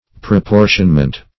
Meaning of proportionment. proportionment synonyms, pronunciation, spelling and more from Free Dictionary.
Search Result for " proportionment" : The Collaborative International Dictionary of English v.0.48: Proportionment \Pro*por"tion*ment\, n. The act or process of dividing out proportionally.